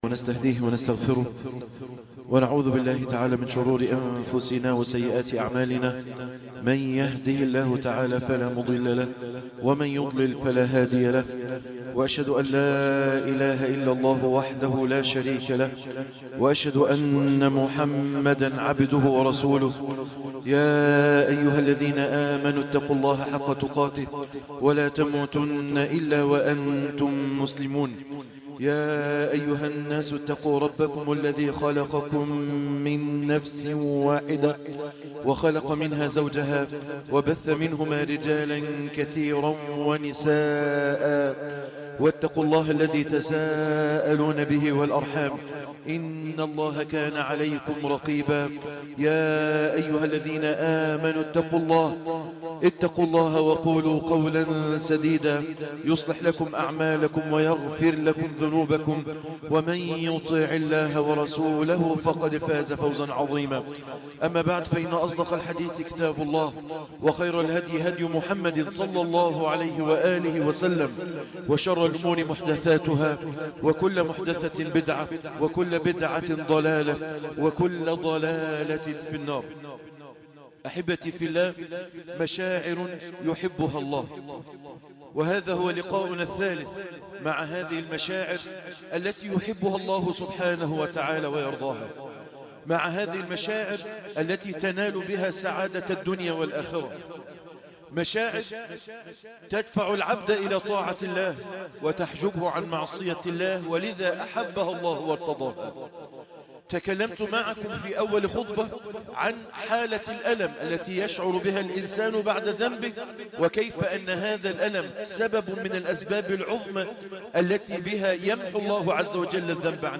الخوف من الله _ خطبة الجمعة